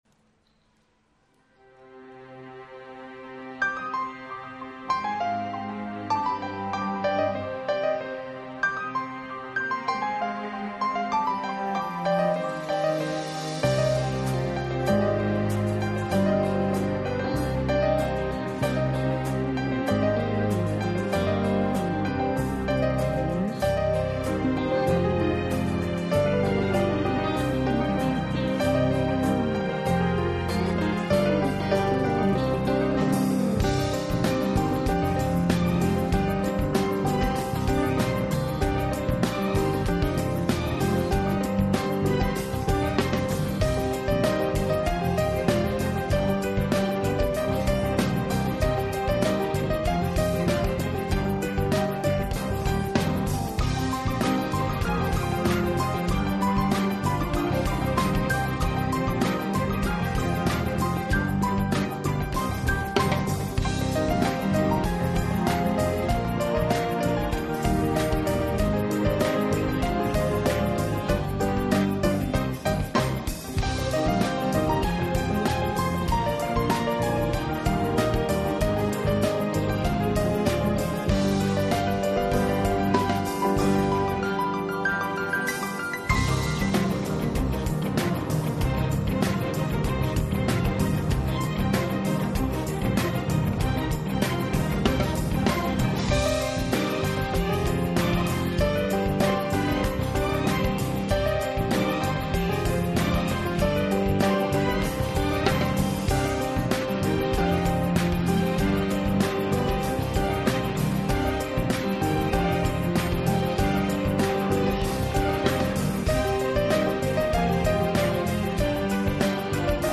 生涯首演LIVE SHOW全记录。